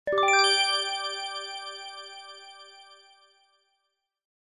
two-tone-chime.mp3